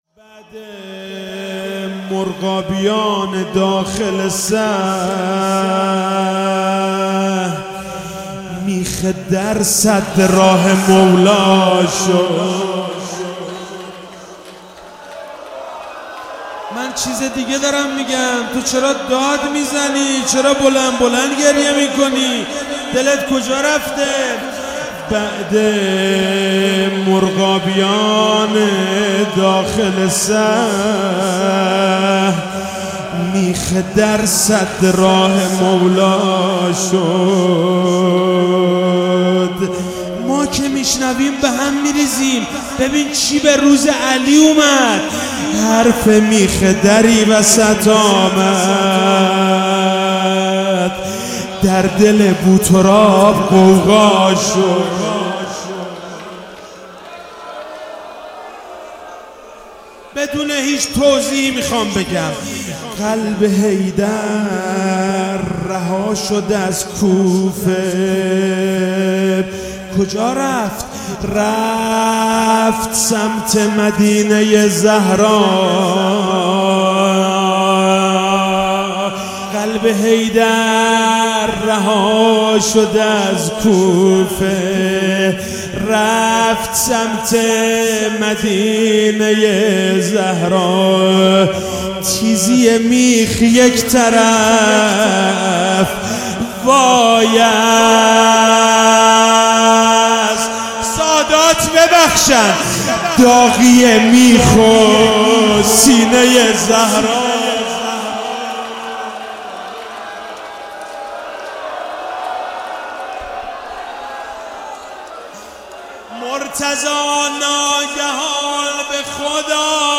شب 19 رمضان97 - روضه